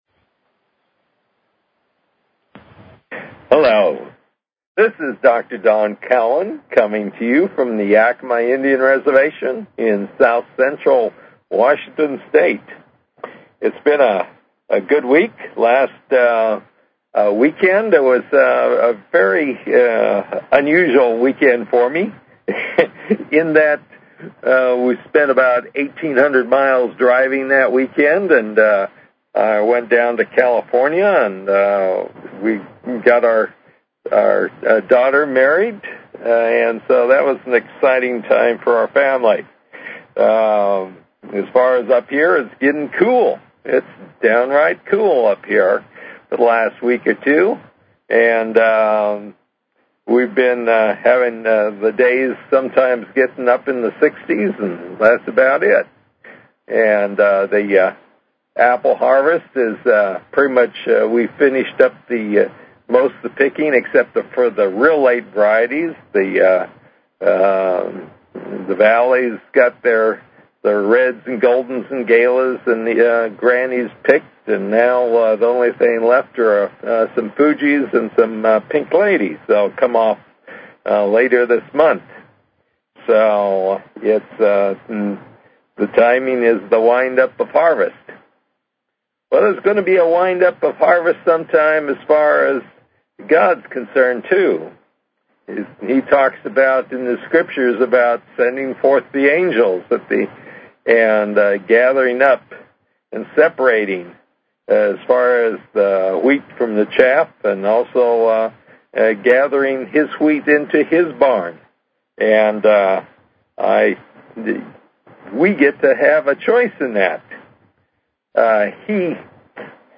Show Headline New_Redeaming_Spirituality Show Sub Headline Courtesy of BBS Radio New Redeaming Spirituality - October 6, 2007 New Redeaming Spirituality Please consider subscribing to this talk show.